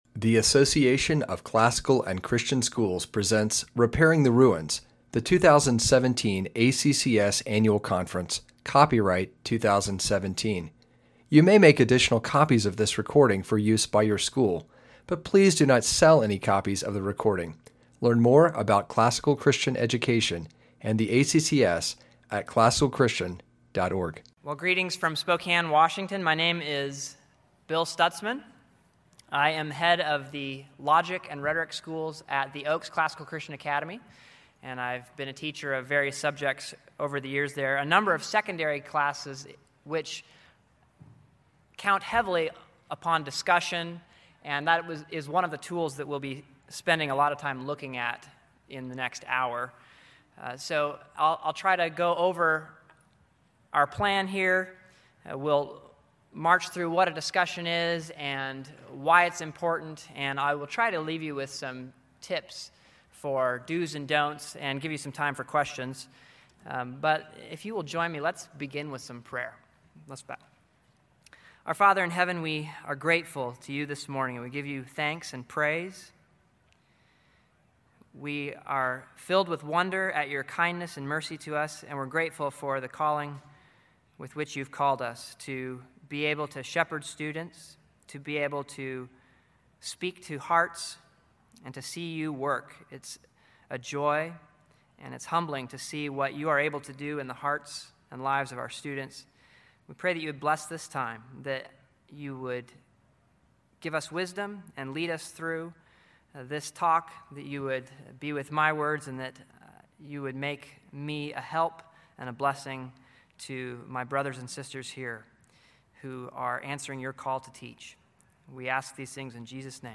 2017 Foundations Talk | 1:04:11 | 7-12, General Classroom
Speaker Additional Materials The Association of Classical & Christian Schools presents Repairing the Ruins, the ACCS annual conference, copyright ACCS.